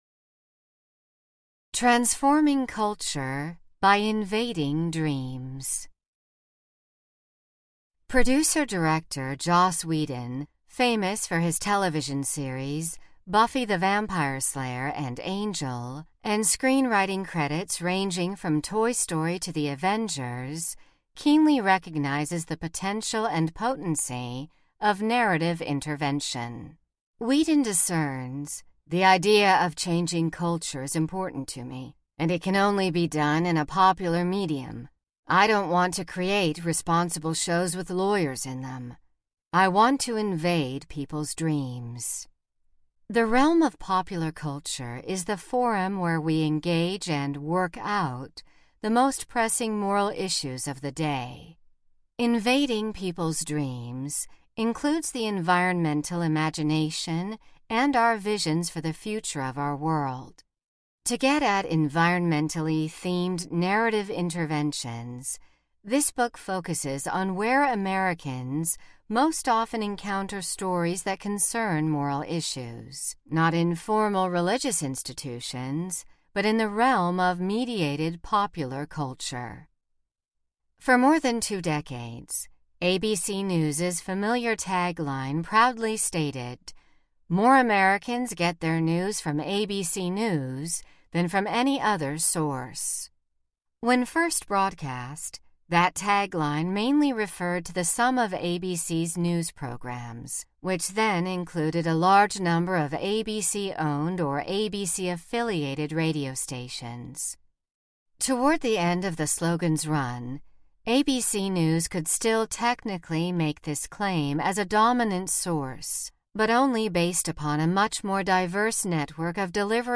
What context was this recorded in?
13.9 Hrs. – Unabridged